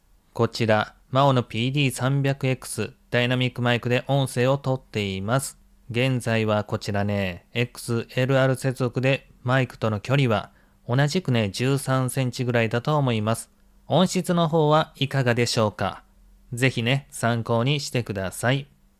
MAONO PD300XT レビュー！XLR接続の音声：マイクとの距離13cm
私個人的には、どれも納得できる温かみある音質に驚きを隠せないでいる現状です。
XLR接続はノイズの影響が受けにくい事と解像度の高い音質に安心感がヤバい！